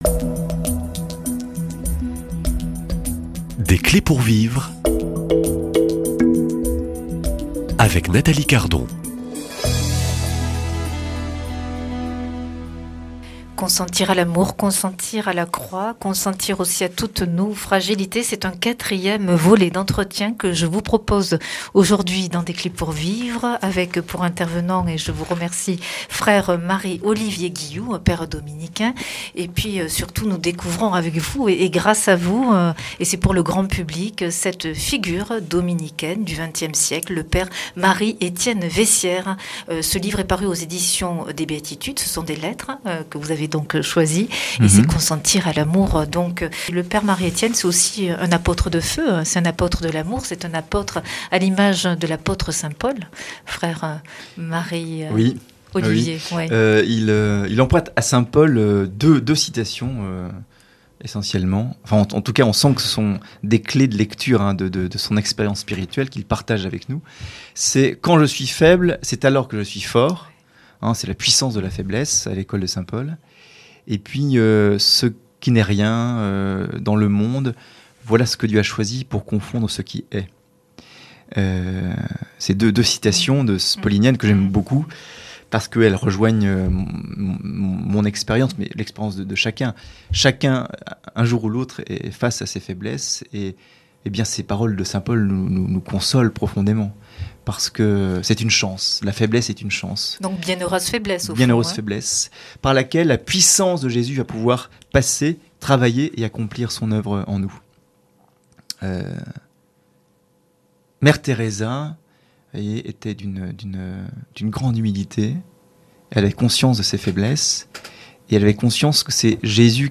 [ Rediffusion ] La puissance de la faiblesse à l'école de l'apôtre Saint Paul Invité